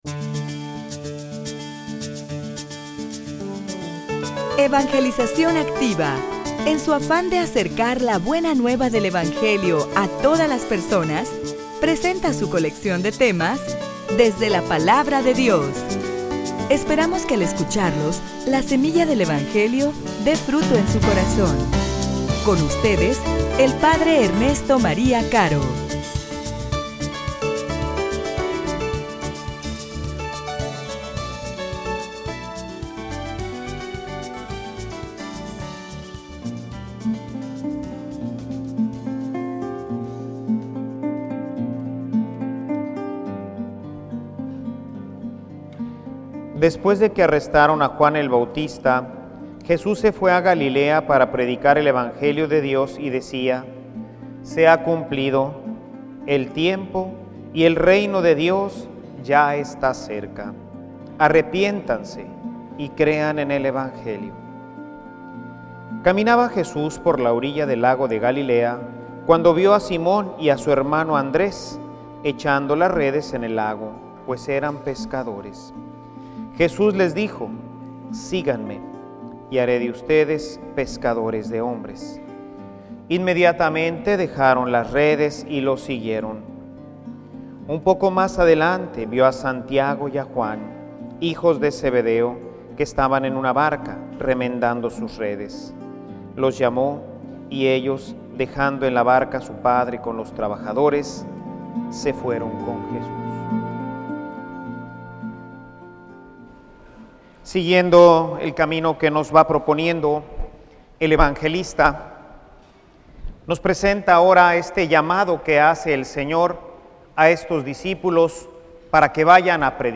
homilia_Te_necesito_a_ti.mp3